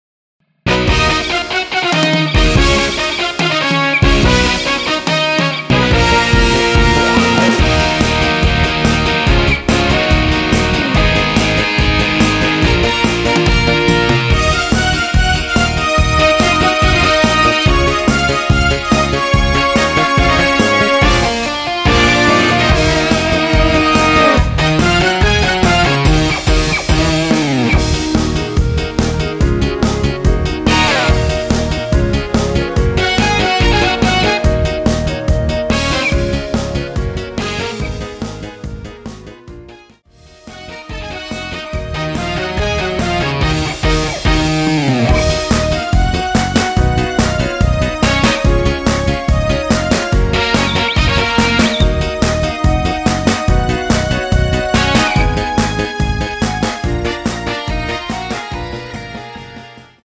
원키에서(-1)내린 멜로디 포함된 MR 입니다.(미리듣기 참조)
Ebm
퀄리티가 너무 좋아요
앞부분30초, 뒷부분30초씩 편집해서 올려 드리고 있습니다.
중간에 음이 끈어지고 다시 나오는 이유는